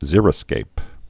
(zîrĭ-skāp)